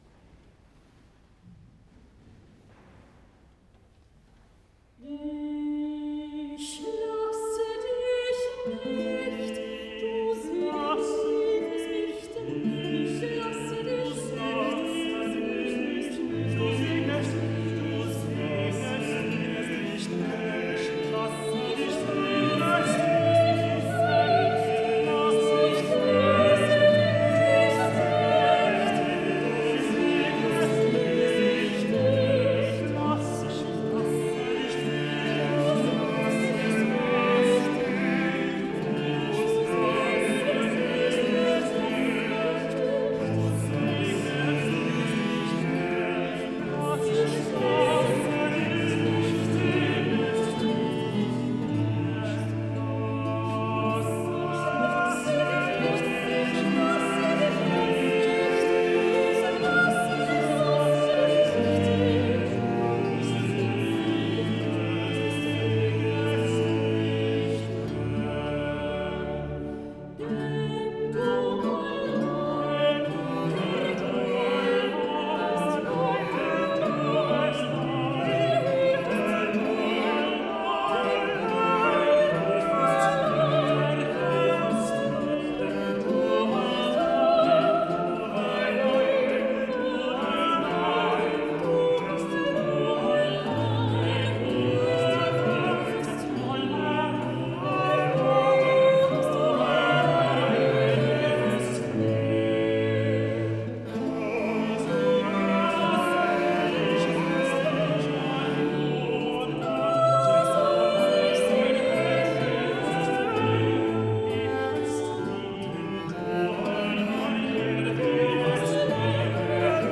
Avec l’autorisation de RTS-Espace 2, vous pouvez ici réécouter le concert des Fontaines d’Israël de Schein donné à la collégiale de St-Ursanne en 2018.